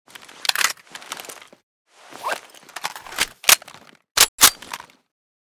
p90_reload_empty.ogg.bak